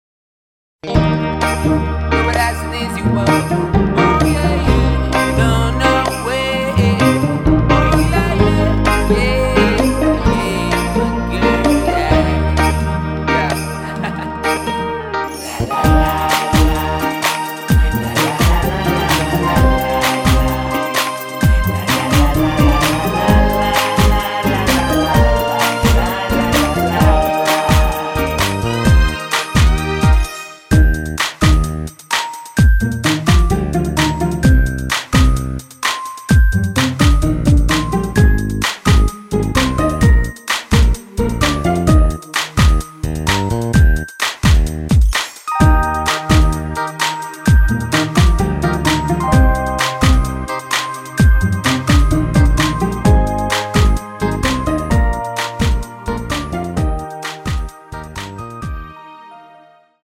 Eb
앞부분30초, 뒷부분30초씩 편집해서 올려 드리고 있습니다.